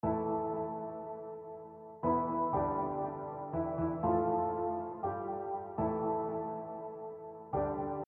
闷闷不乐的哭声
描述：一个非常令人毛骨悚然的低沉的哭声寻求帮助。痛苦。
Tag: 闷响 帮助